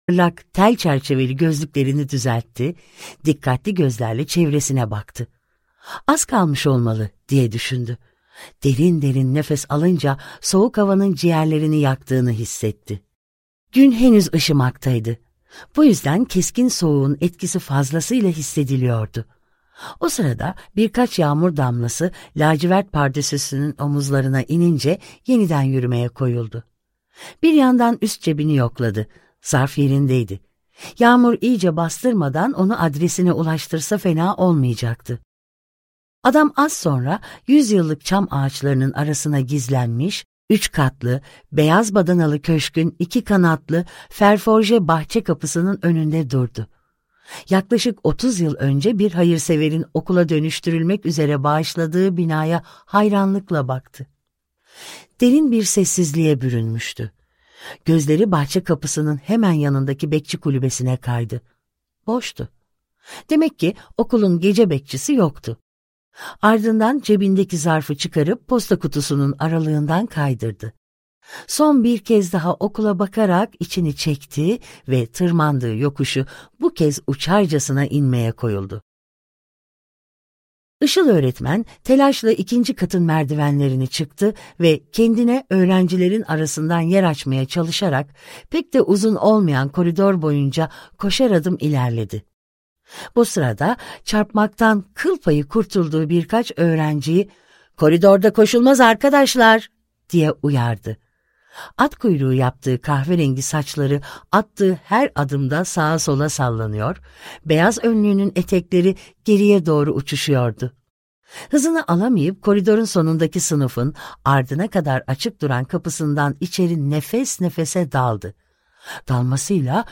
Kadim Şehrin Şifreleri - Seslenen Kitap